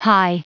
Prononciation du mot hie en anglais (fichier audio)
Prononciation du mot : hie